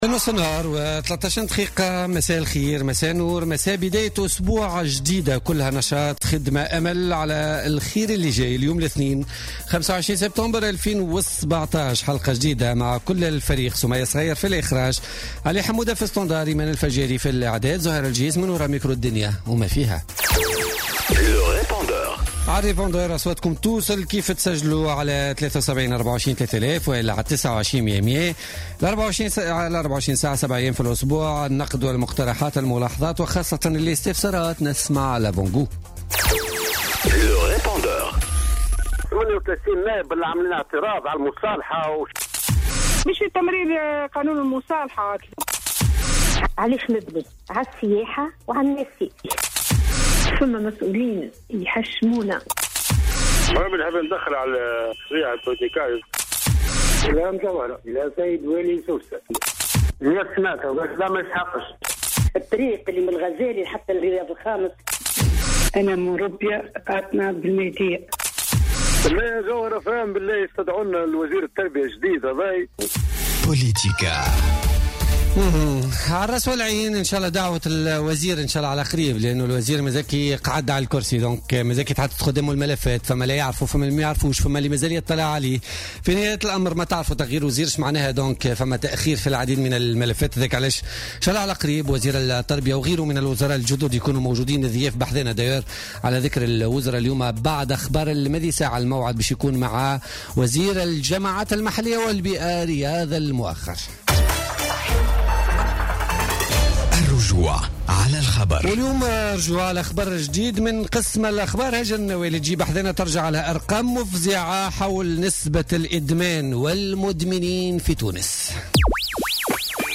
Riadh Mouakher ministre des Affaires locales et de l'environnement, invité de politica